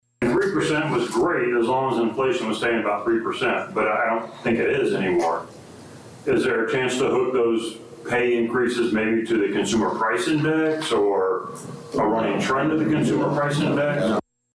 Supervisor of Assessments Matt Long had another suggestion….